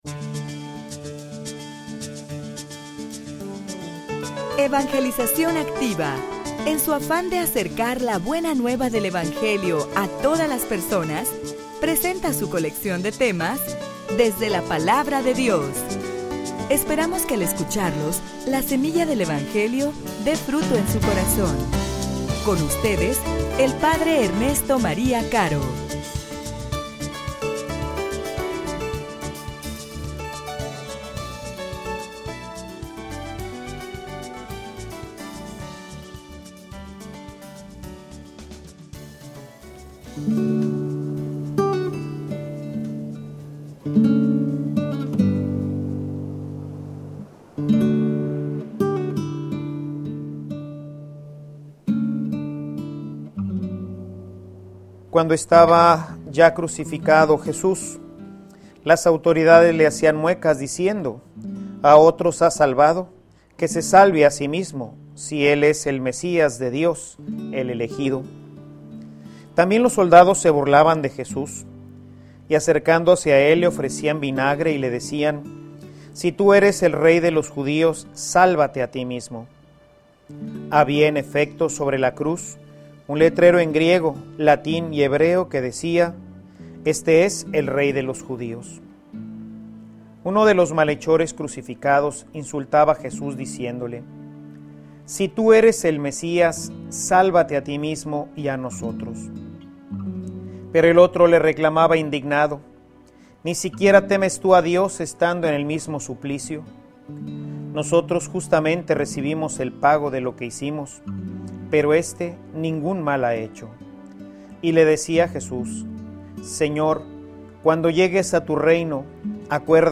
homilia_Un_Rey_diferente.mp3